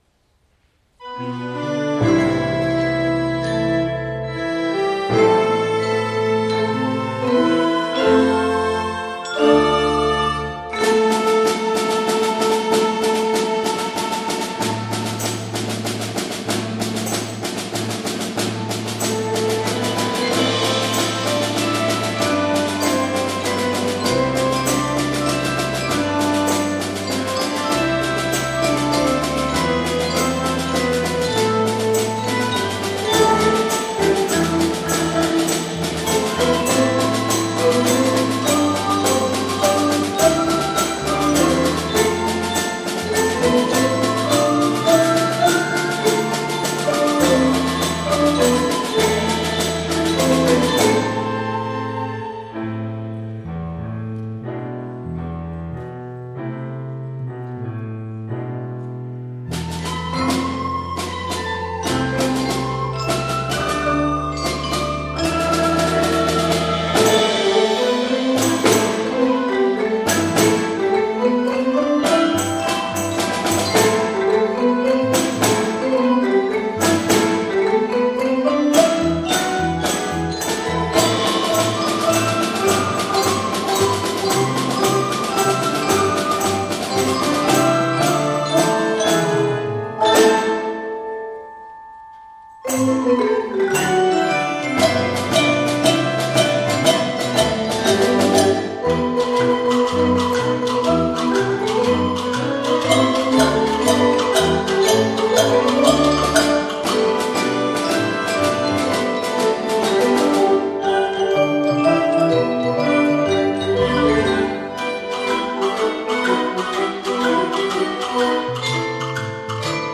市内小学校の音楽会がFOSTERホールにて開催されました。
合奏「パイレーツオブ・カリビアン・メドレー」
荘厳なメロディ、愉快で陽気な拍子、勇ましいスピード感、難易度の高いメドレーを、完璧に演奏しました。